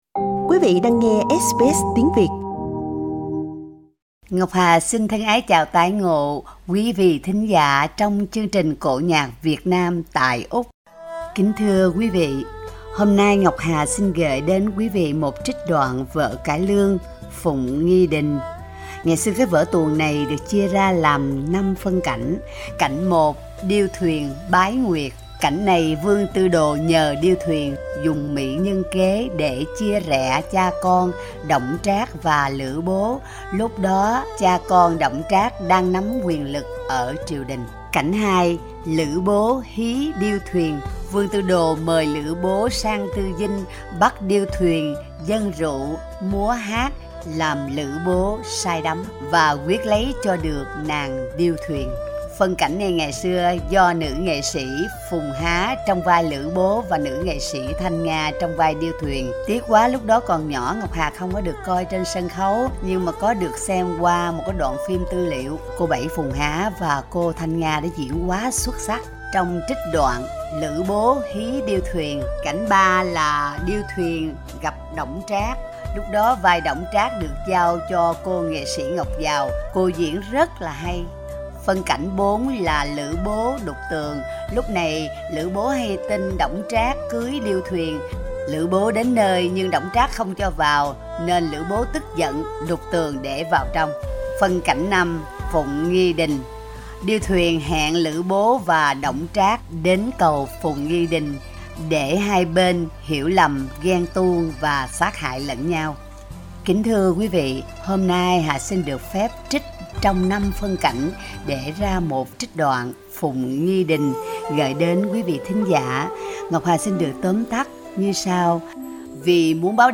Phụng Nghi Đình là một vở tuồng cải lương với nội dung vay mượn từ tích truyện của Trung Quốc, xoay quanh các nhân vật chính Điêu Thuyền – Lữ Bố – Đổng Trác – Vương Doãn, được biên soạn riêng để ca diễn theo lối cải lương.